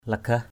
lagah.mp3